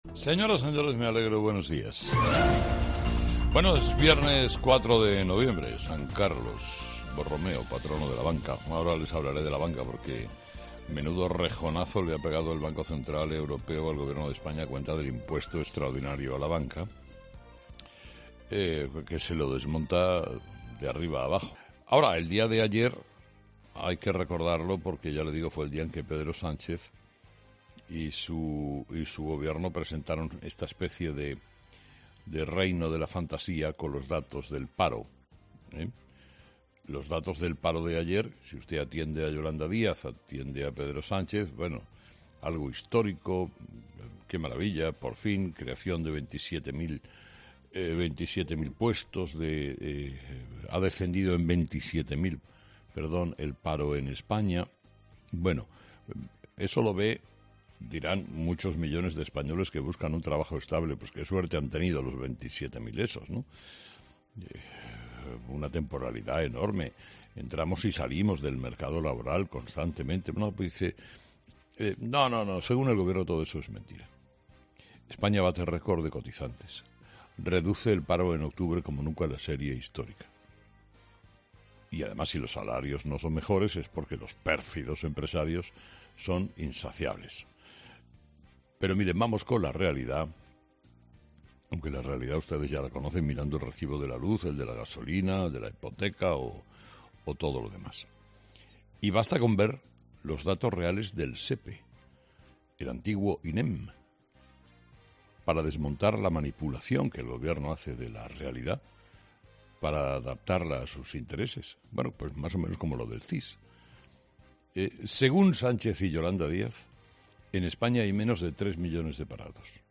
Sin embargo, esta mañana, Carlos Herrera en su monólogo de ‘Herrera en COPE’ ha explicado a sus oyentes cuál es la realidad que se esconde detrás de estos números.